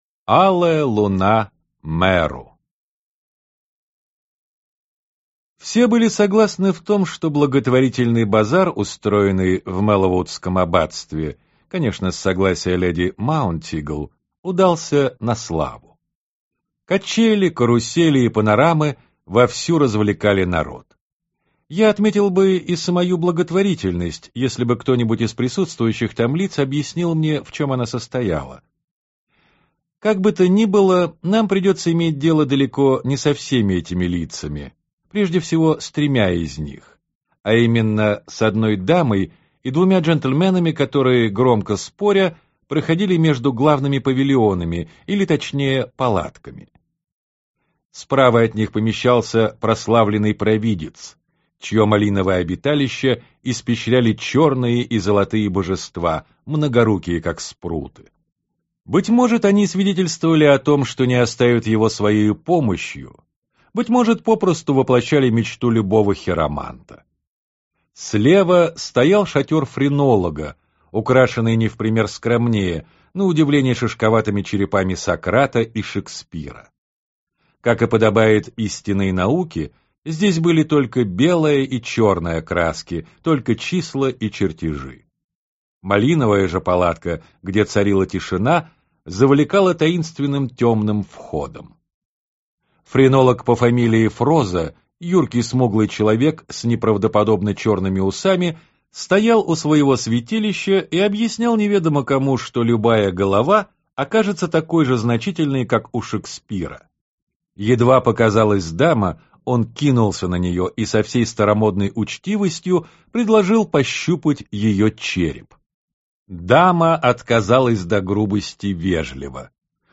Аудиокнига Алая луна Меру (рассказы про отца Брауна) | Библиотека аудиокниг